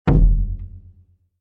Surdo-6.mp3